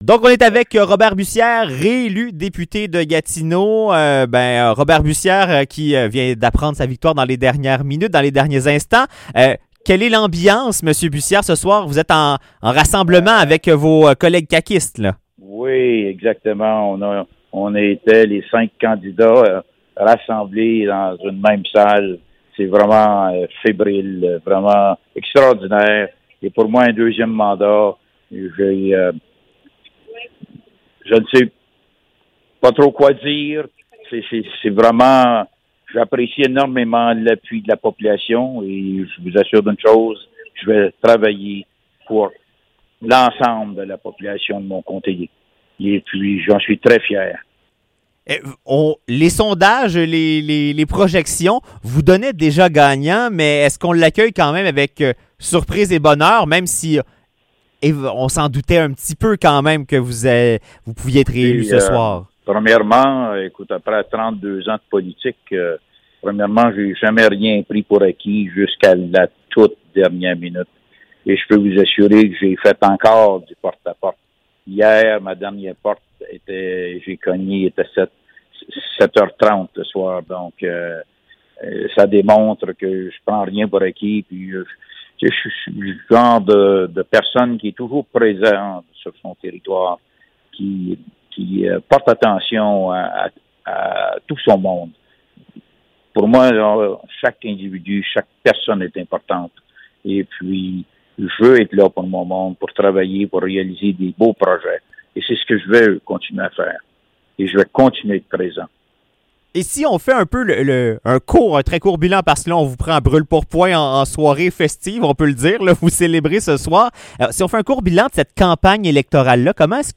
Entrevue avec Robert Bussière, réélu comme député de Gatineau
lors de la soirée électorale.